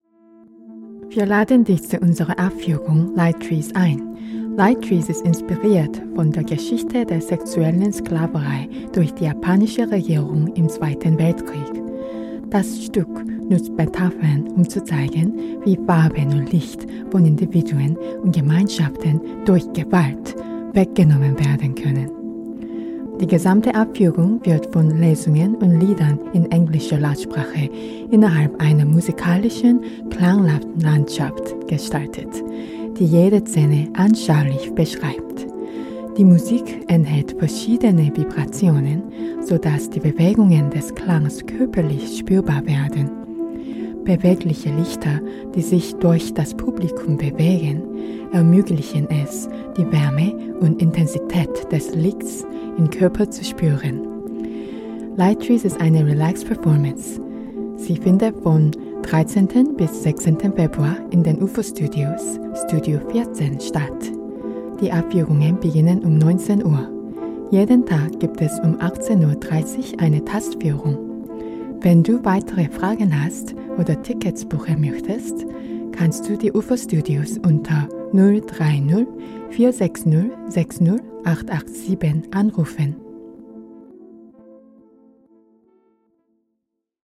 Audio Flyer: